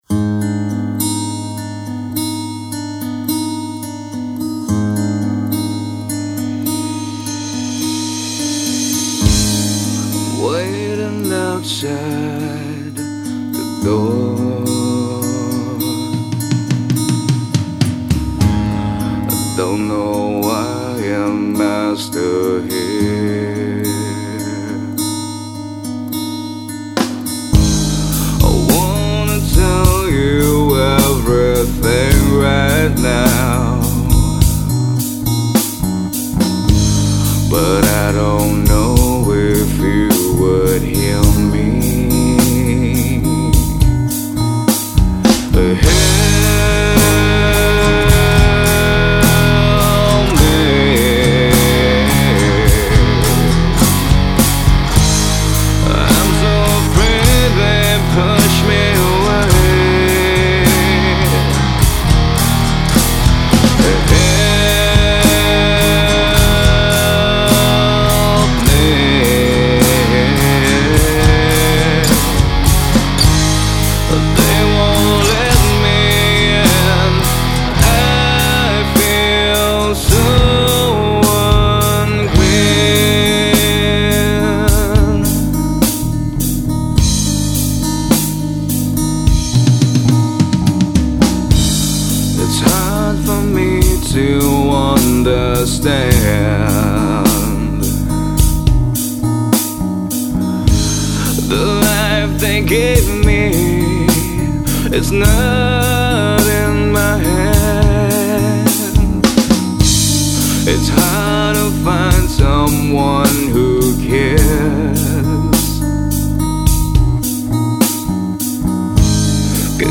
Singer | Songwriter
soul-stirring vocals accompanied by an acoustic guitar